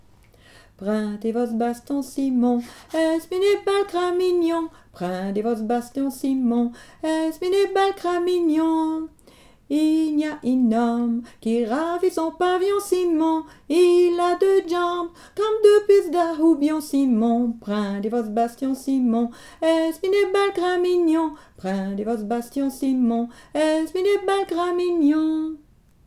Genre : chant
Type : cramignon
Aire culturelle d'origine : Haute Ardenne
Lieu d'enregistrement : Malmedy